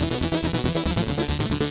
Index of /musicradar/8-bit-bonanza-samples/FM Arp Loops
CS_FMArp B_140-E.wav